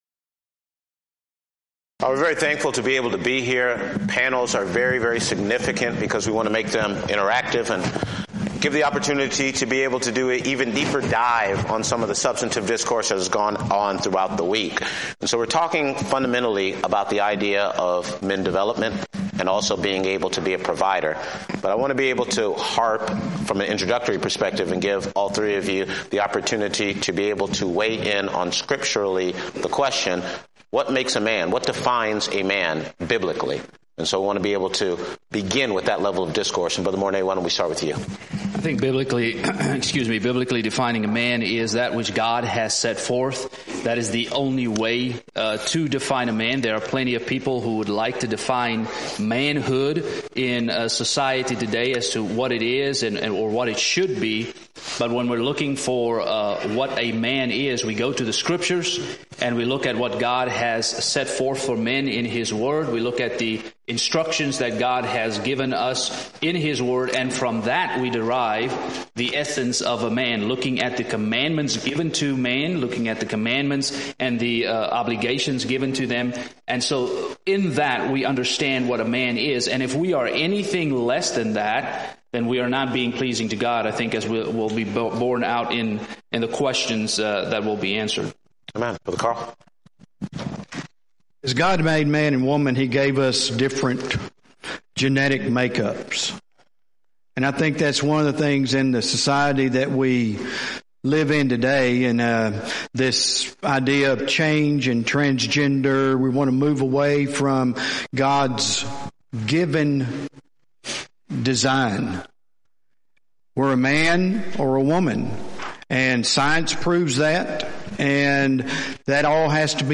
Event: 6th Annual BCS Men's Development Conference
If you would like to order audio or video copies of this lecture, please contact our office and reference asset: 2022MDC21